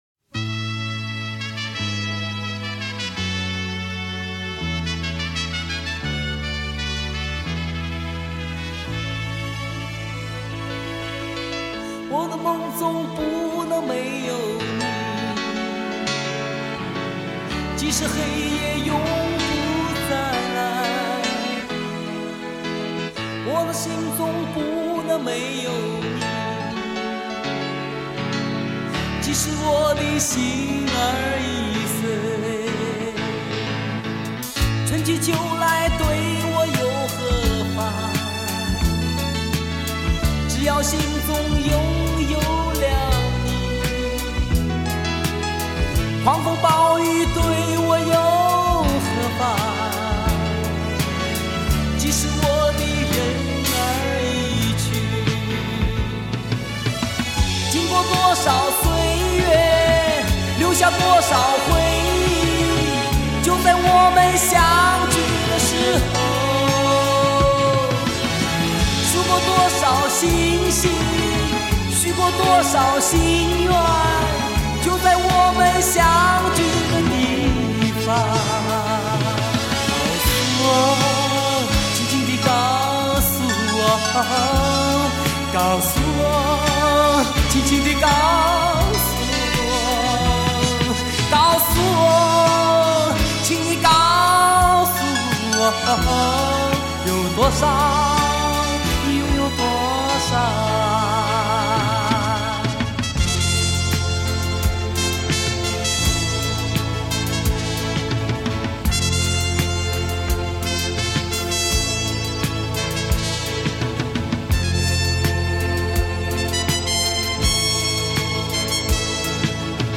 磁带数字化